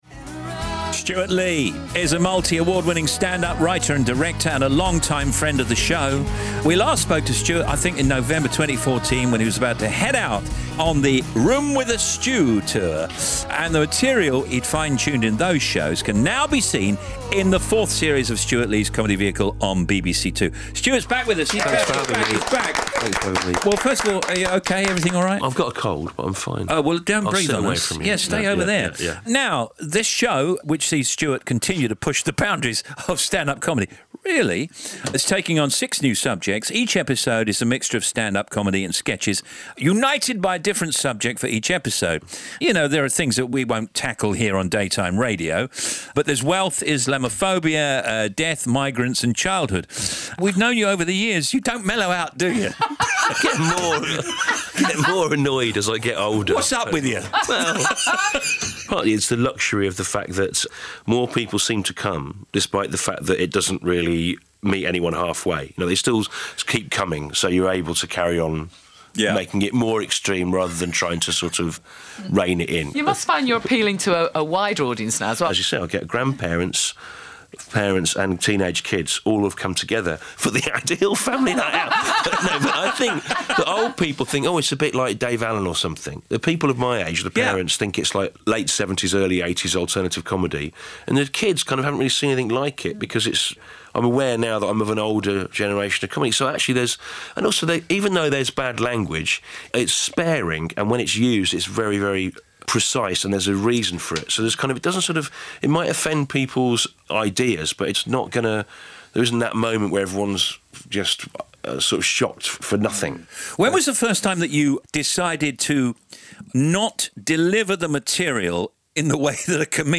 Steve Wright Interview – 3rd March 2016 : Stewart Lee - 41st Best Standup Ever!